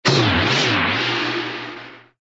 cogbldg_settle.ogg